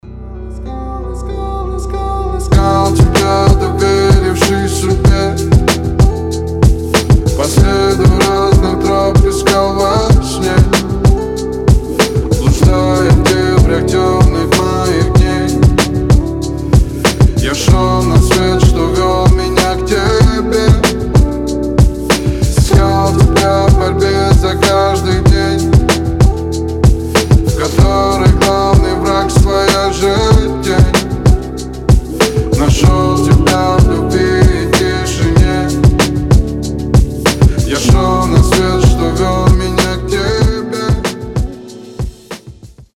мужской голос
красивые
лирика